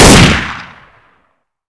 galil-1.wav